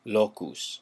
Ääntäminen
IPA : /spɒt/